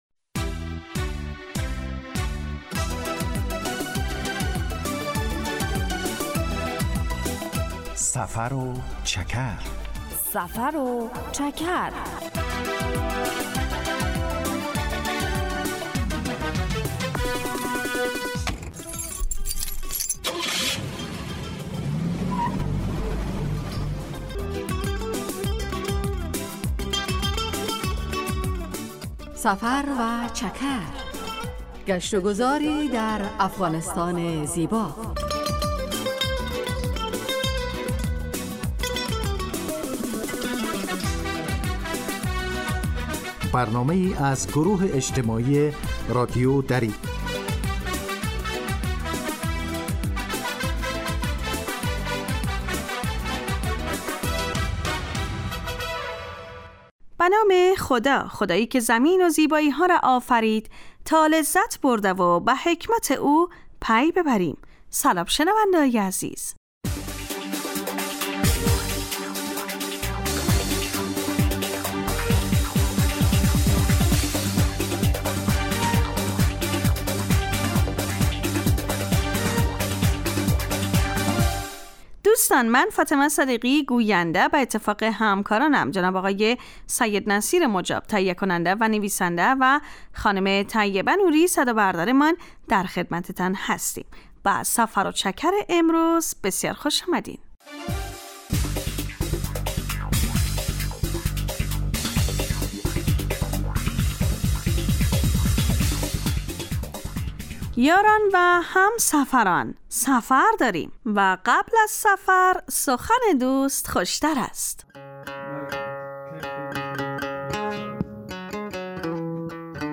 سفر و چکر برنامه ای 15 دقیقه از نشرات رادیو دری است که به معرفی ولایات و مناطق مختلف افغانستان می پردازد. در این برنامه مخاطبان با جغرافیای شهری و فرهنگ و آداب و سنن افغانی آشنا می شوند. در سفر و چکر ؛ علاوه بر معلومات مفید، گزارش و گفتگو های جالب و آهنگ های متناسب هم تقدیم می شود.